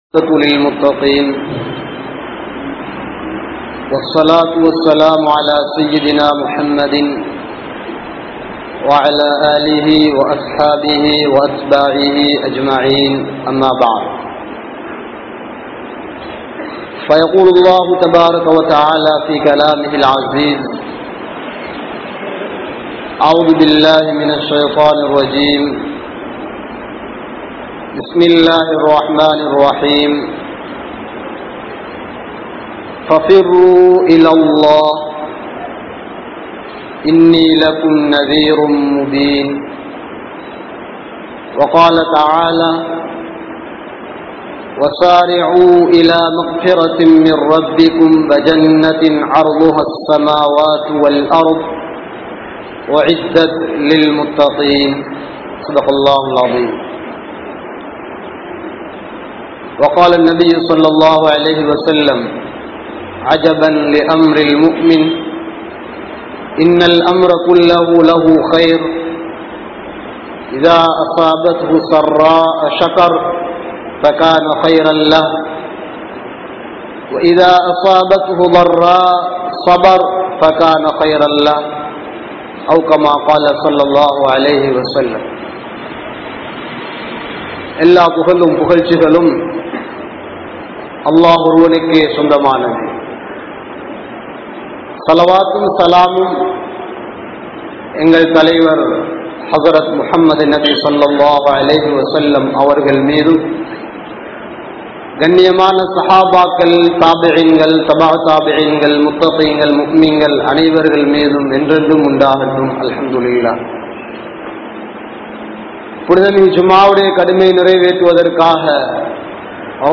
History of Muslims in Sri Lanka | Audio Bayans | All Ceylon Muslim Youth Community | Addalaichenai